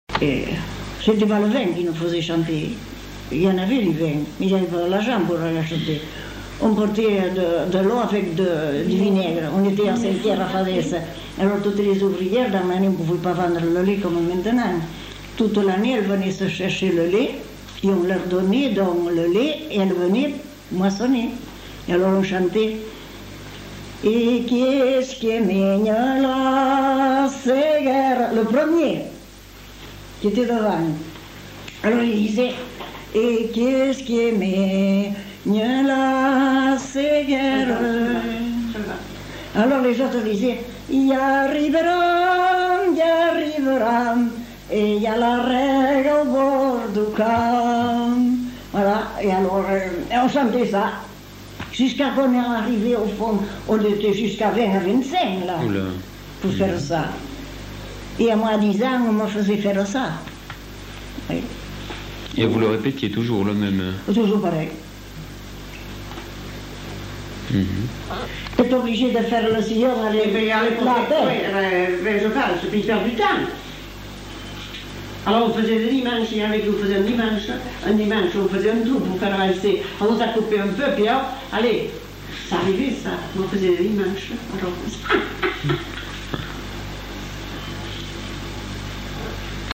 Lieu : Mont-de-Marsan
Genre : chant
Effectif : 1
Type de voix : voix de femme
Production du son : chanté
Notes consultables : Chant de moisson.